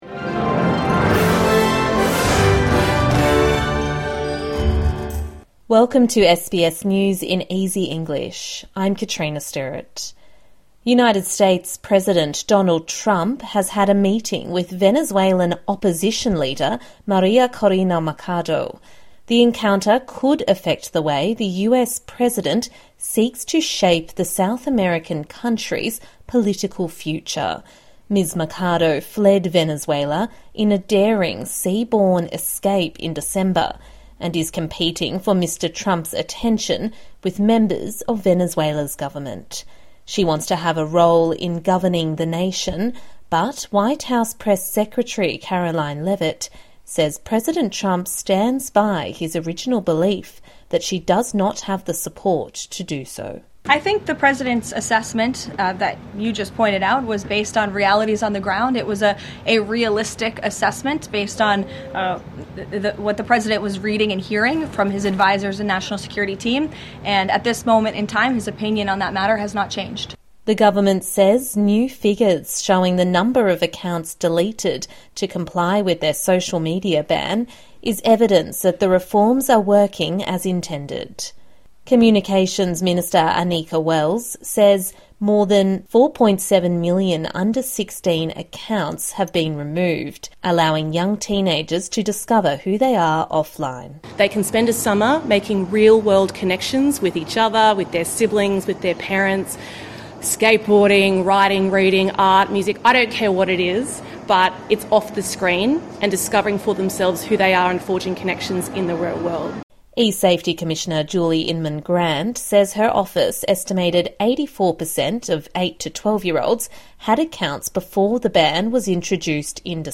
A daily 5 minute news bulletin for English learners and people with a disability.